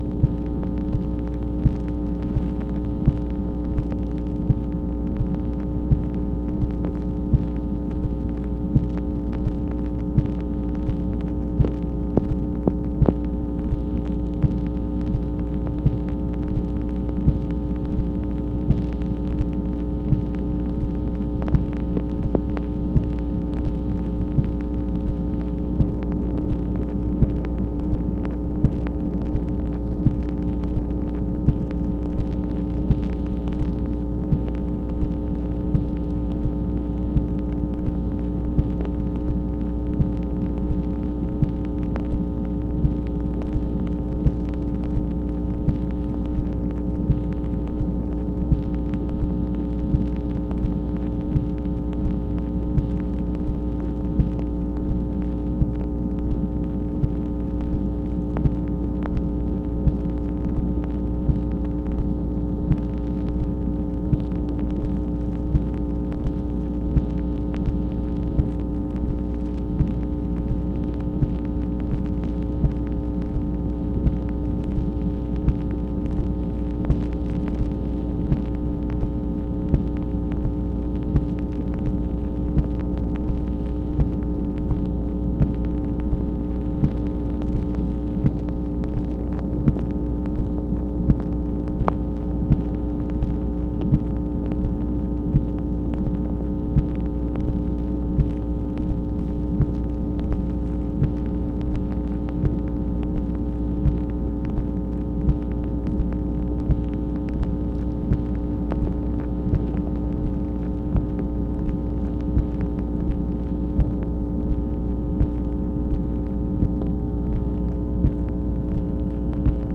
MACHINE NOISE, September 30, 1966
Secret White House Tapes | Lyndon B. Johnson Presidency